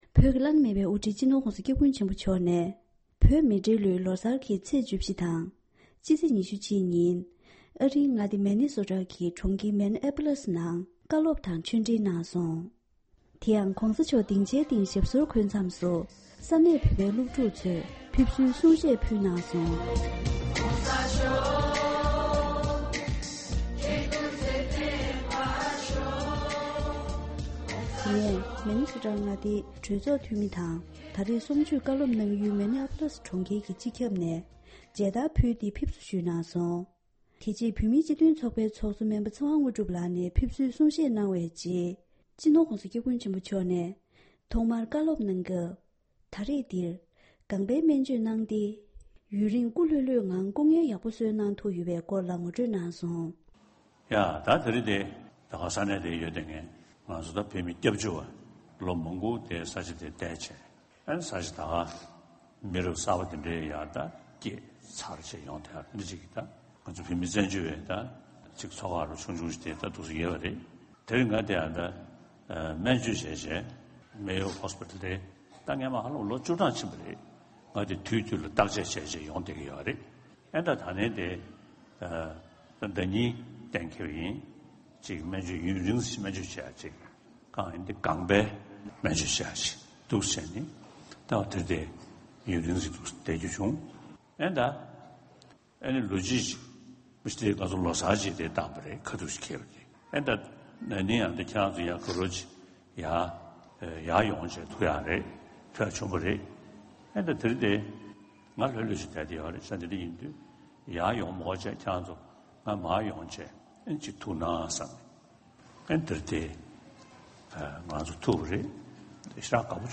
དེ་ཡང་འདི་གའི་ཆེད་གཏོང་གསར་འགོད་པས་ཨ་རིའི་Minneapolisནས་གནས་ཚུལ་སྤེལ་བ་ལྟར་ན།